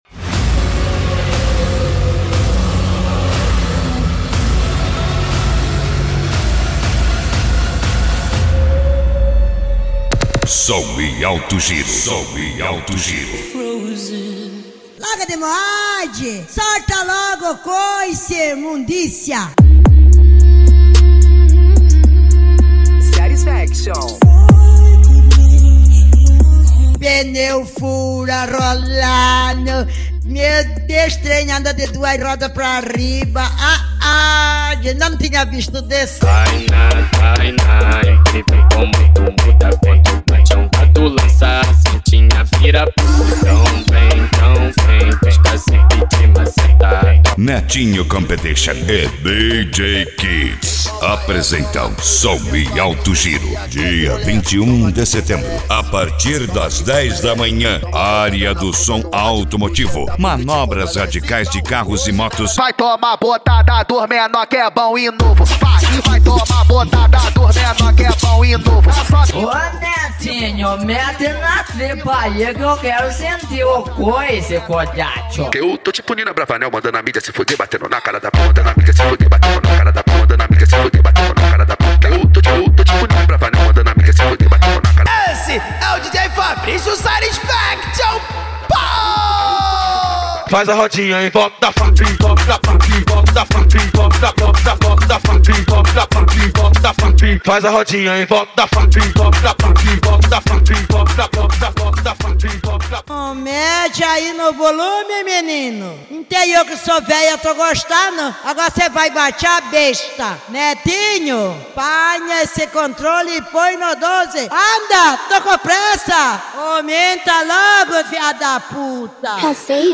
Bass
Funk
Mega Funk